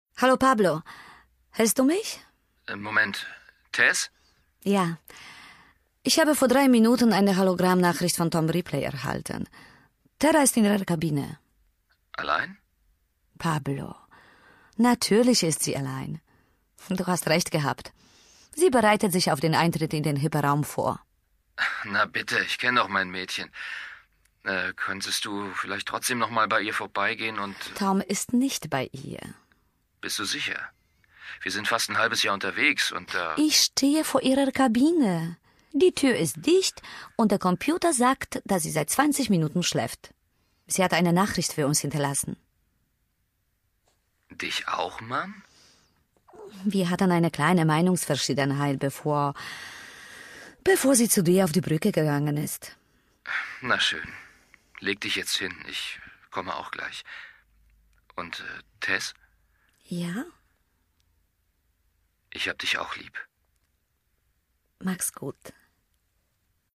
Polnisch, Deutsch (mit Akzent), Englisch (mit Akzent)
Telephone Call ° Deutsch